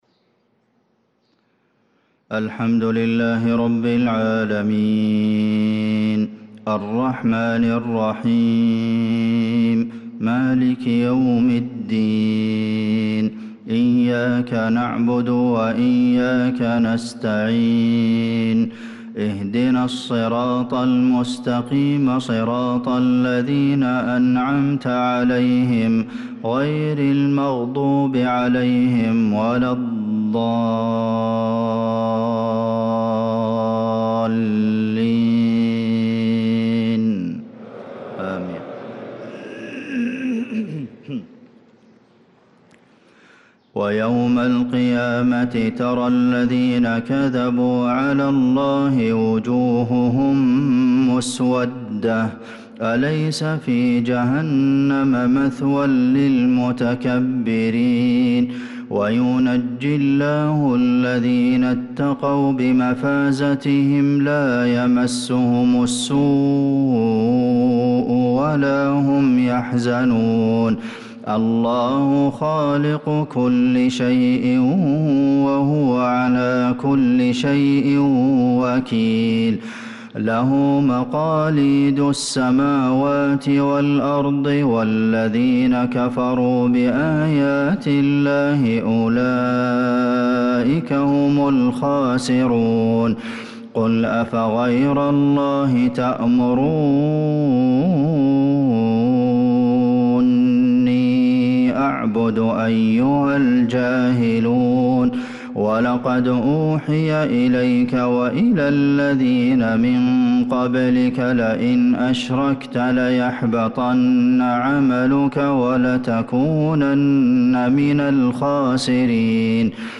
صلاة الفجر للقارئ عبدالمحسن القاسم 29 شوال 1445 هـ